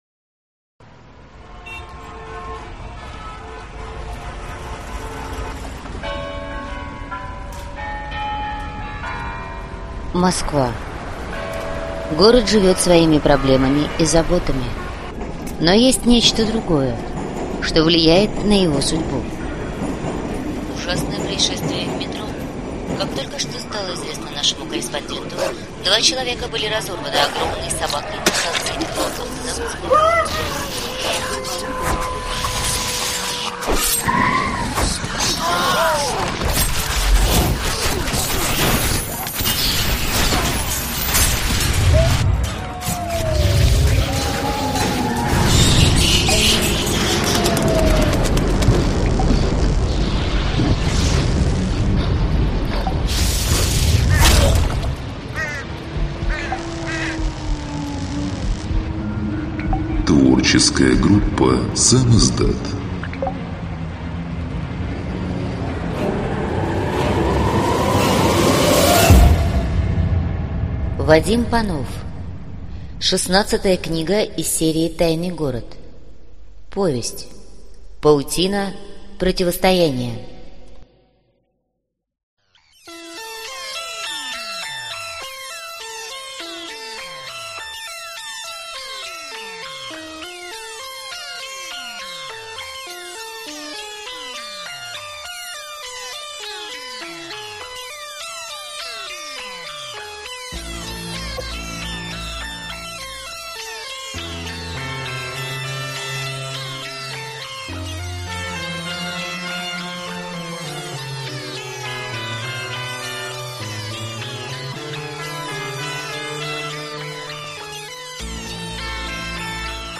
Аудиокнига Паутина противостояния - купить, скачать и слушать онлайн | КнигоПоиск